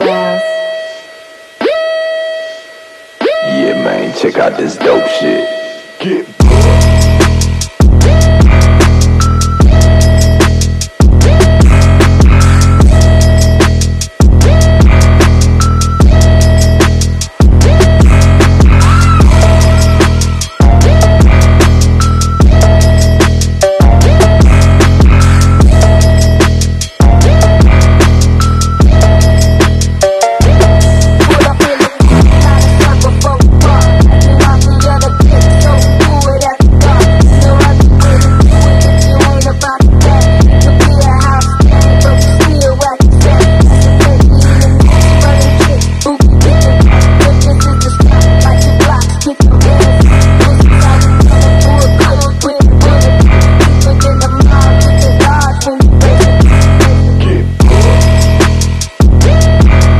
Zx6r Nitrous Dyno Record And Sound Effects Free Download